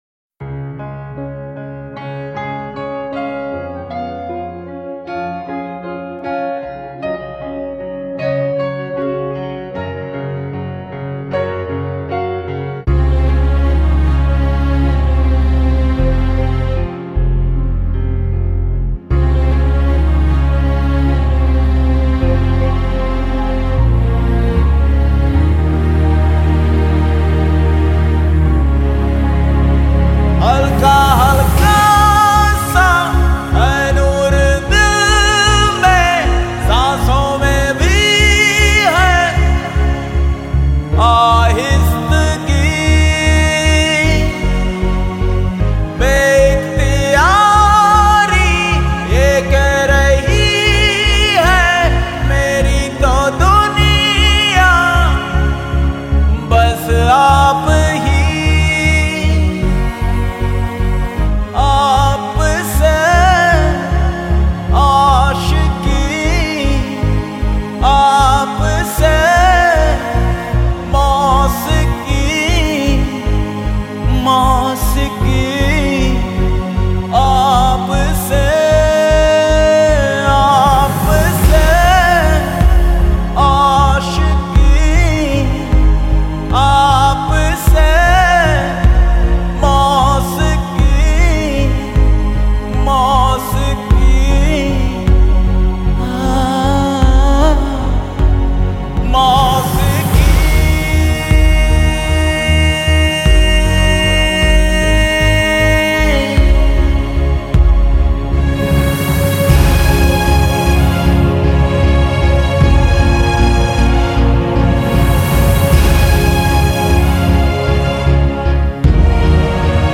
Latest Pop Songs